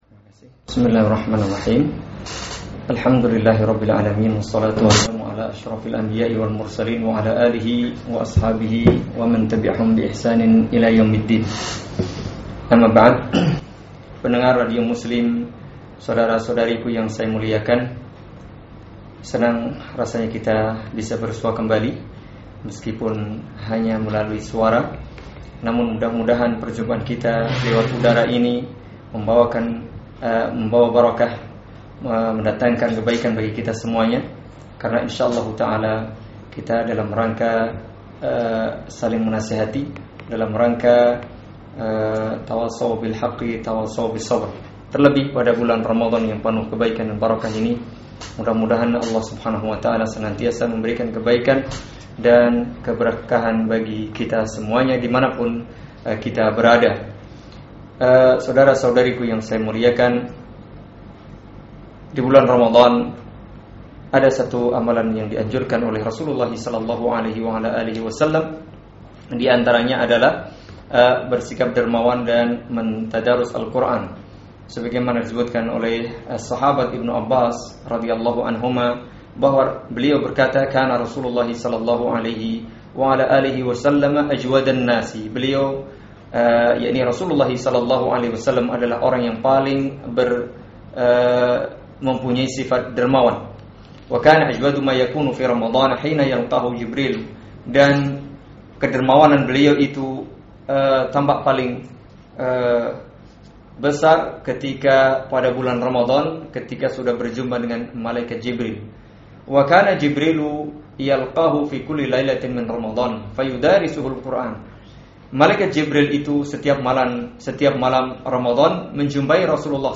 Ceramah Singkat Ramadhan 1433H - Contoh-contoh Kedermawanan di Bulan Ramadhan.mp3